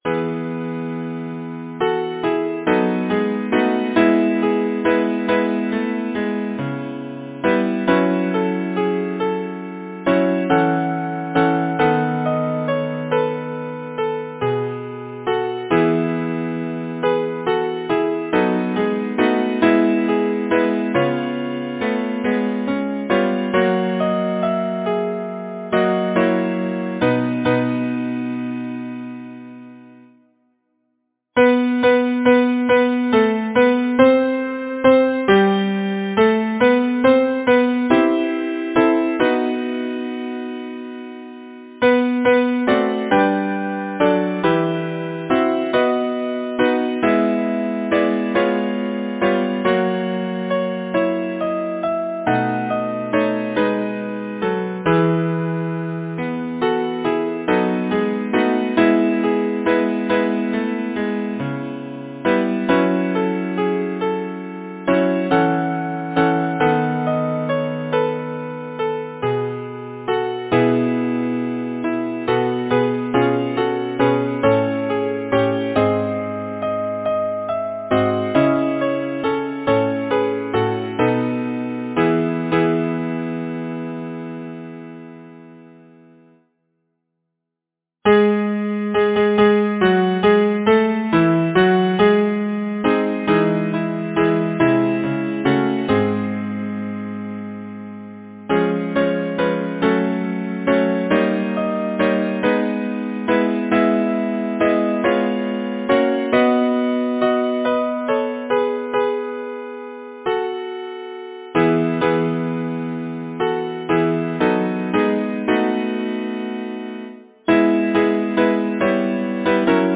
Title: The lover’s song Composer: John Haraden Pratt Lyricist: Edward Rowland Sill Number of voices: 4vv Voicing: SATB Genre: Secular, Partsong
Language: English Instruments: A cappella